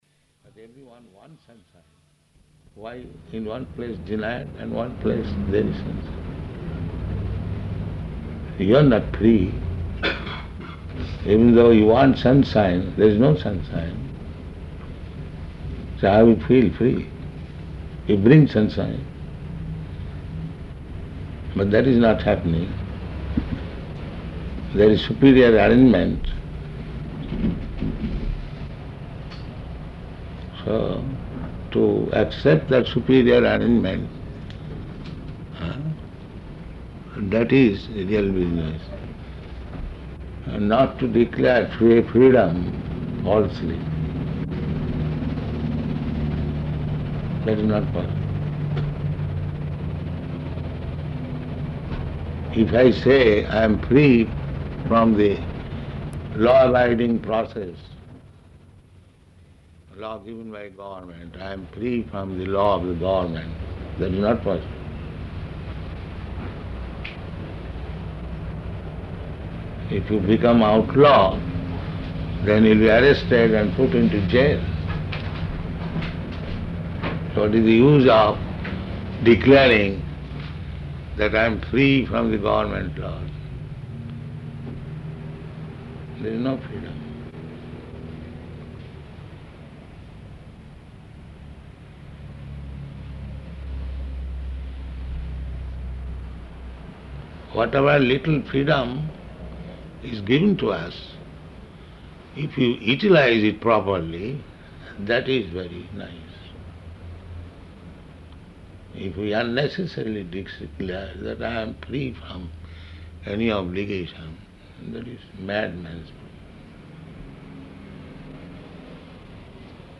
-- Type: Conversation Dated: July 26th 1975 Location: Laguna Beach Audio file